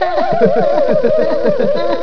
laff.wav